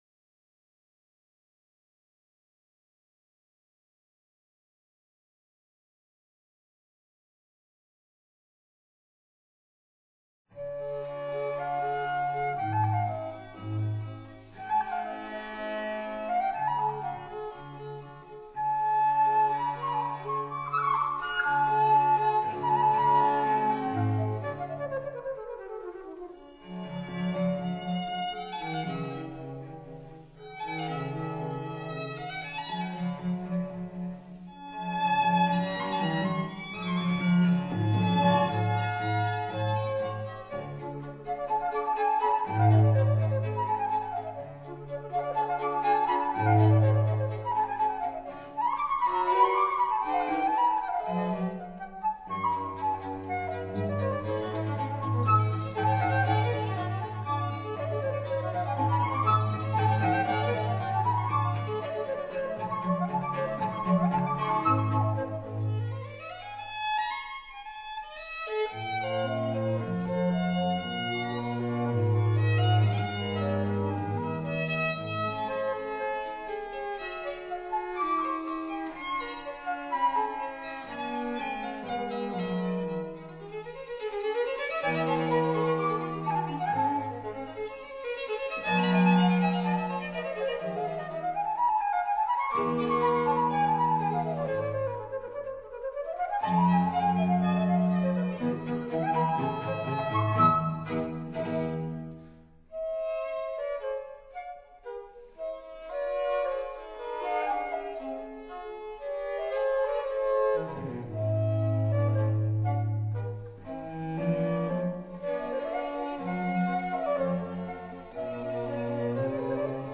Allegro moderato
Baroque Violin
Baroque Viola
Cello